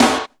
• Dark Snare One Shot B Key 51.wav
Royality free snare drum tuned to the B note. Loudest frequency: 1891Hz
dark-snare-one-shot-b-key-51-Iah.wav